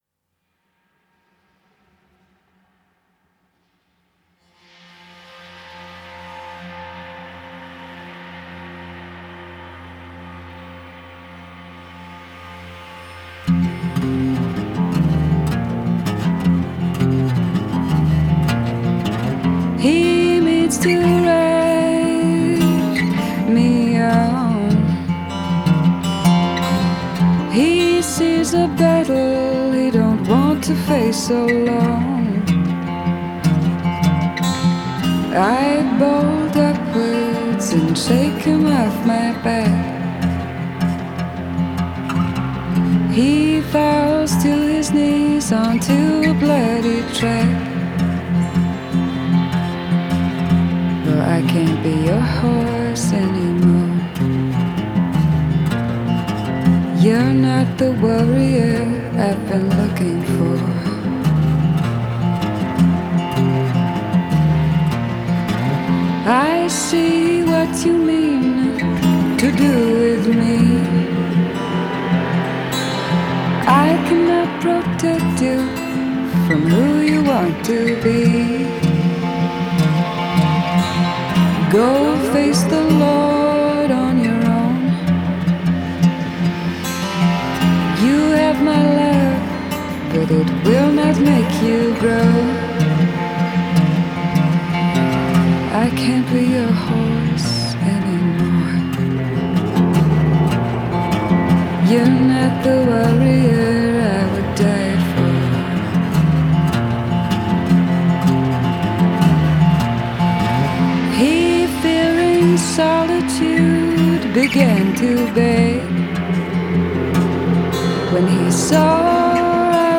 Genre: Indie Folk, Alternative